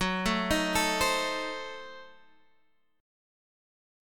F#dim7 Chord